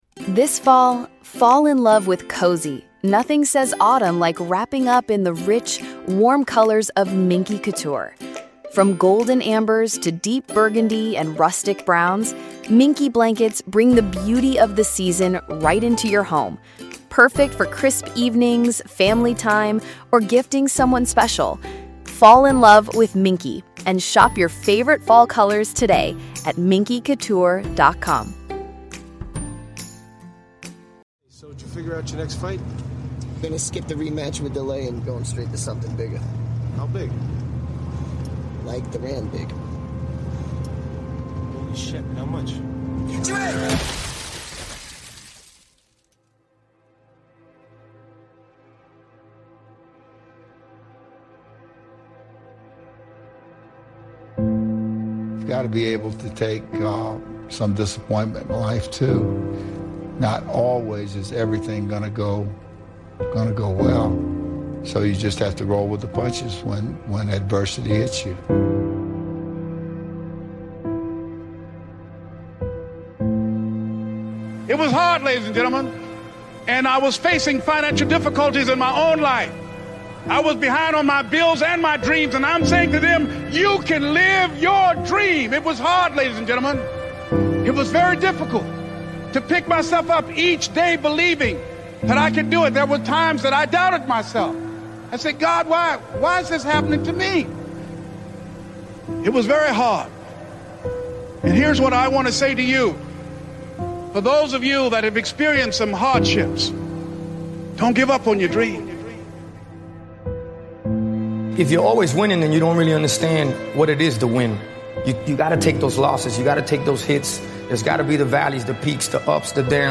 Speakers: Les Brown